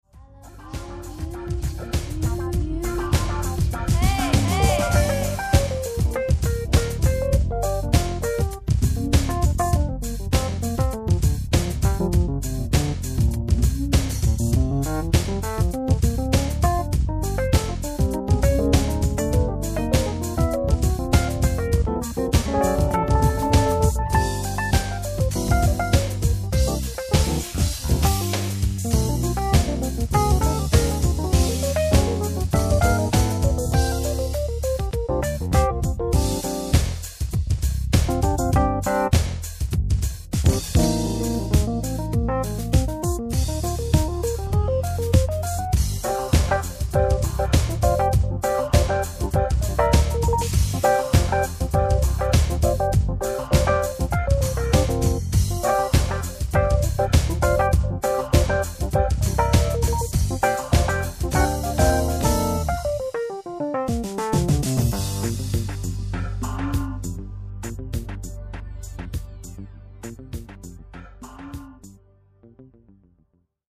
el.piano solo